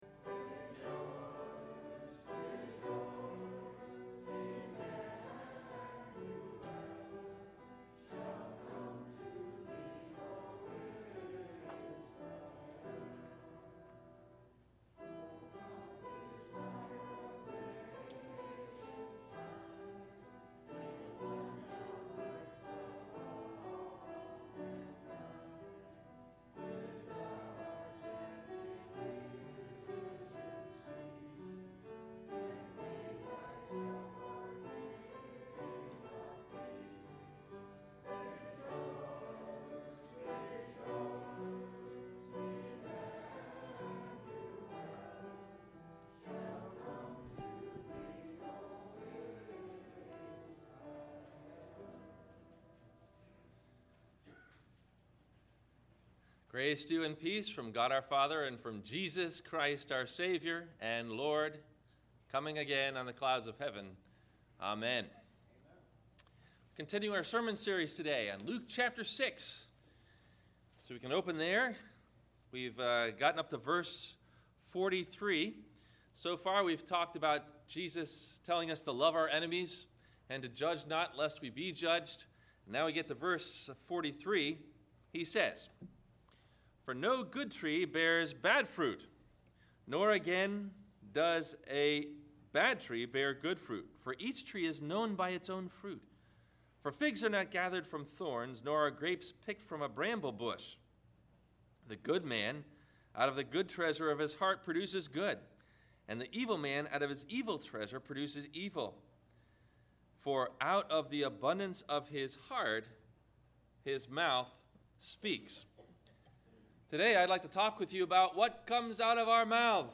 Listen for these questions and answers about The Spiritual Power of Music, below in the 1-part Video or 1-part MP3 Audio Sermon below.